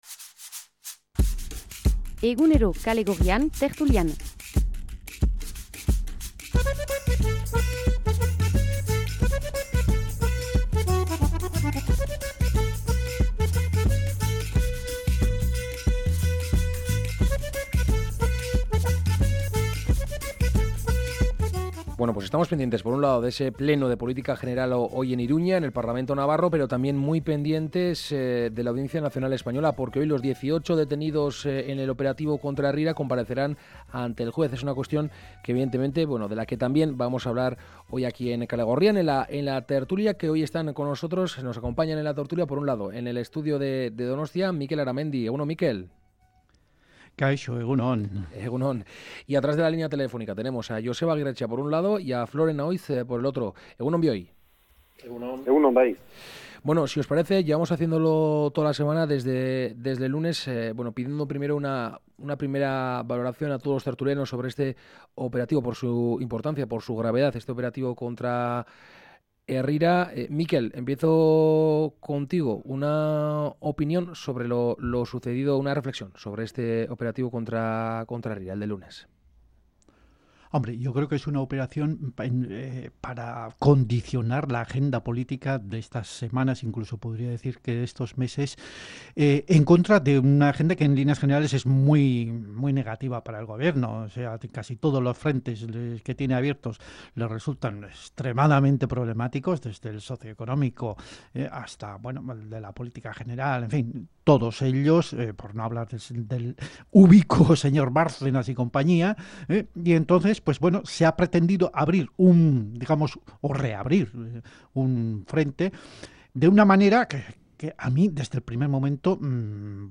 La tertulia de Kale Gorrian
Charlamos y debatimos sobre algunas de las noticias mas comentadas de la semana con nuestros colaboradores habituales.